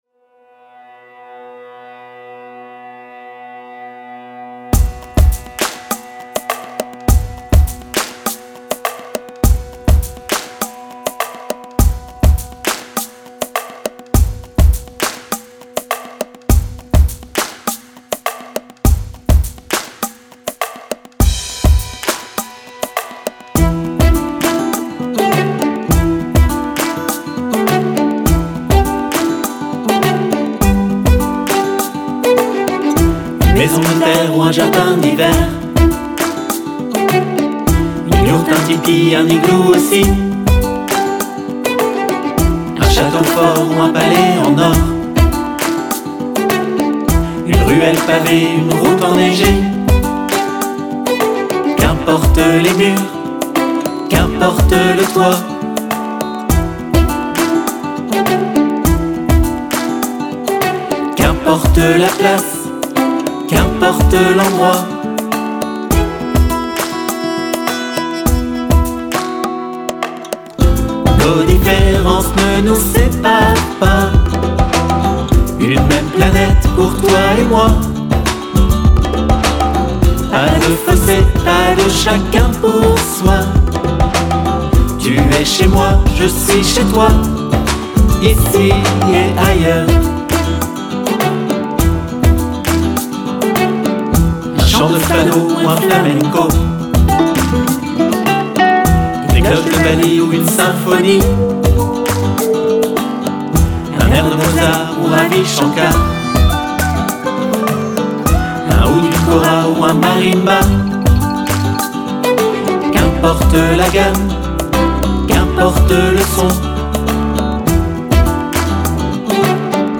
Ici et ailleurs: version chantée